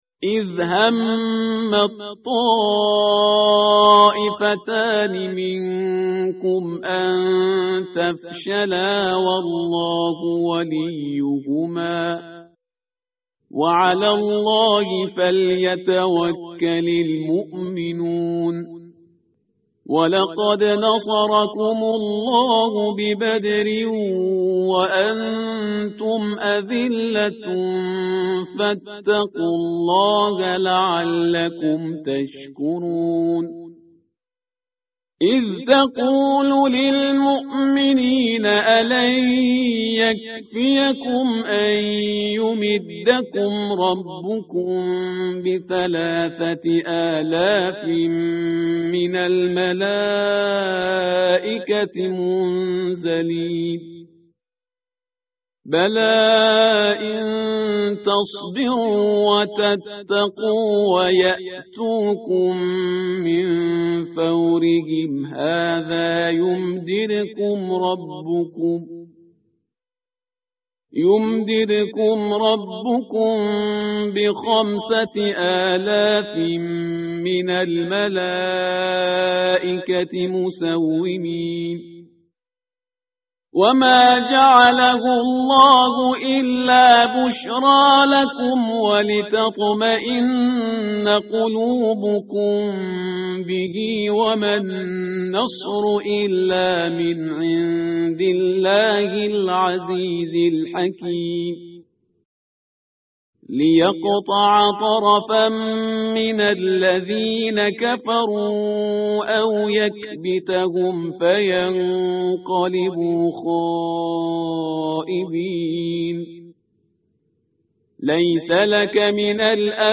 tartil_parhizgar_page_066.mp3